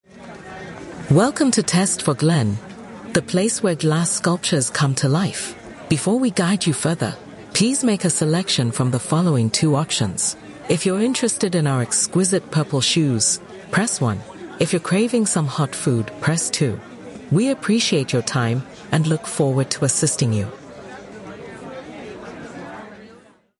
A better customer experience – On Hold